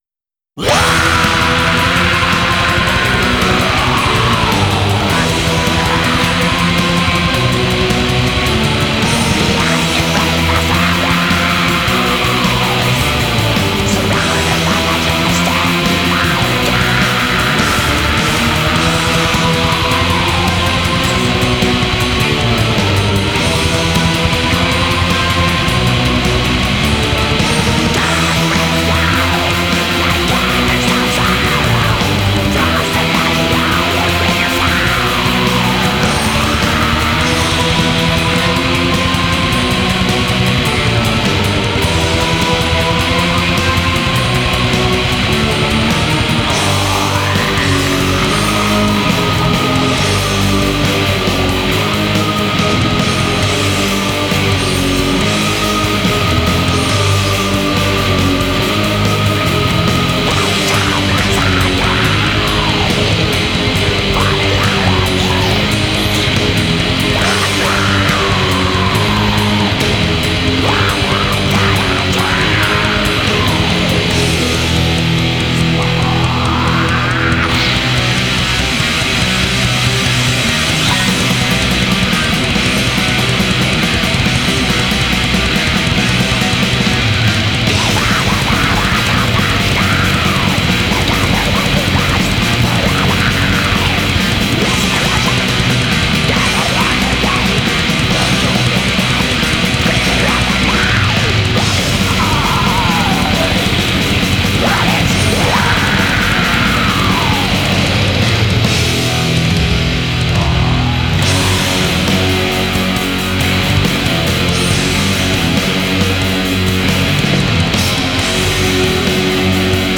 بلک متال نروژی
ریف گیتار بسیار خشن و تند و تیز با ووکال هارش